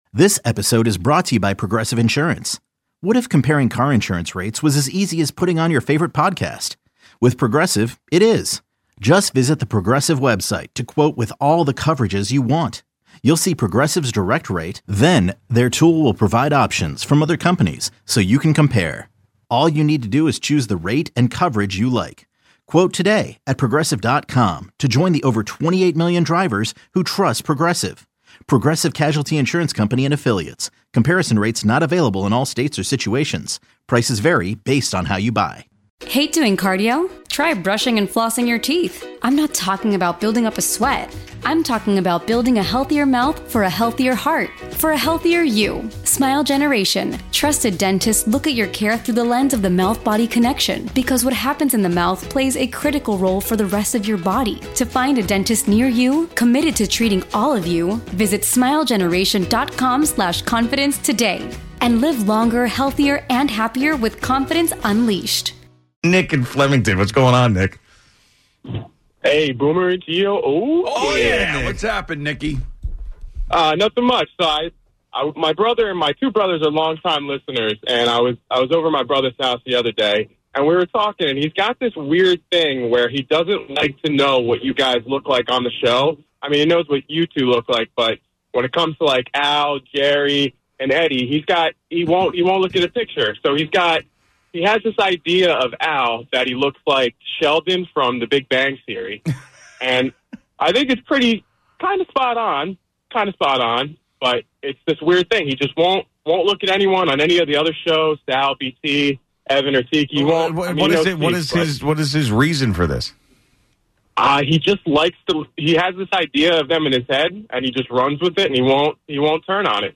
What Hosts & Listeners Look Like Boomer & Gio Audacy Sports, News 4.4 • 676 Ratings 🗓 23 July 2025 ⏱ 9 minutes 🔗 Recording | iTunes | RSS 🧾 Download transcript Summary A caller said his brother listens to all the shows on WFAN, but does not want to know what the hosts look like.